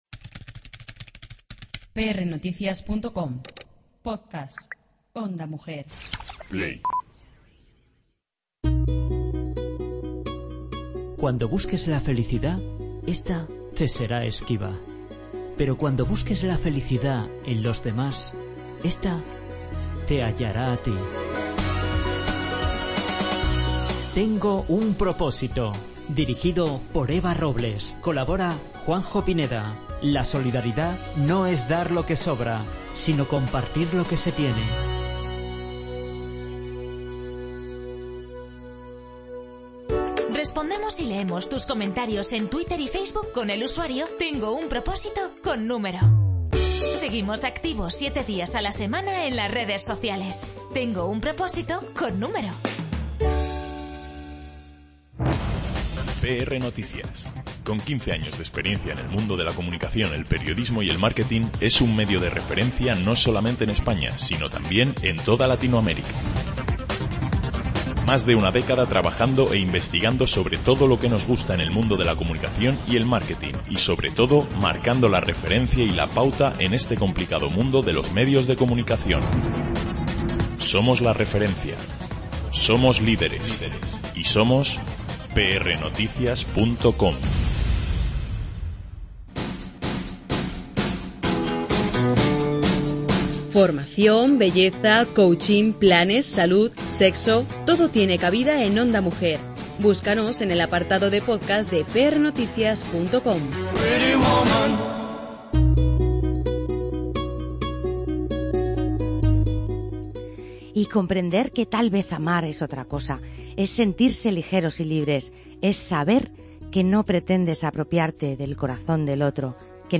Invitamos al estudio de Ondamujer a una representación de estos 43.000 embajadores de la Fundación Carrefour.